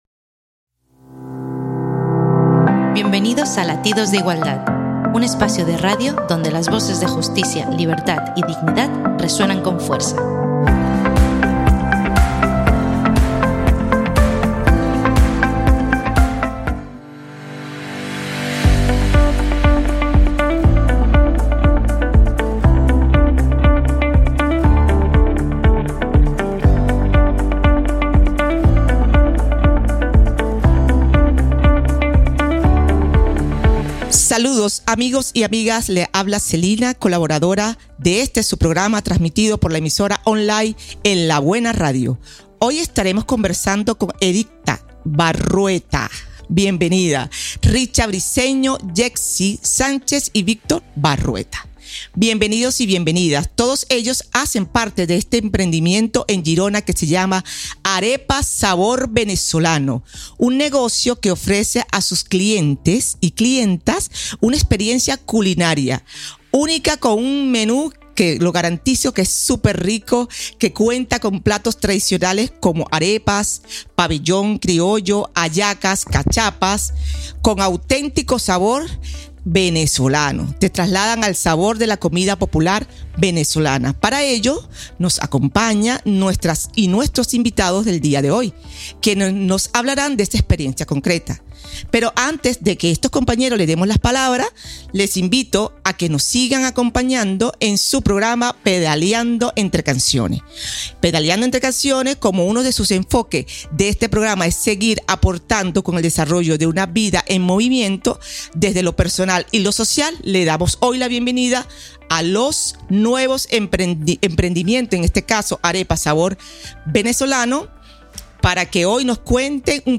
Entrevista amb Arepas sabor Venezolano. Empreniment familiar - En la buena radio